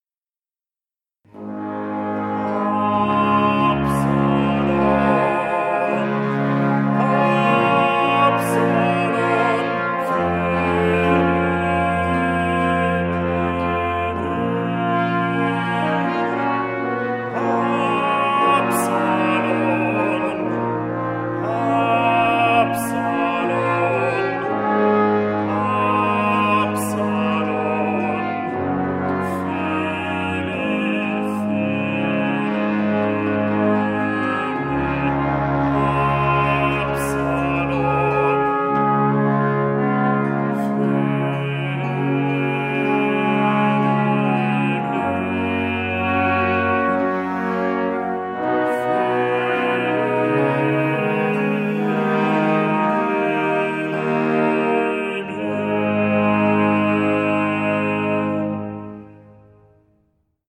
Instrumentalisten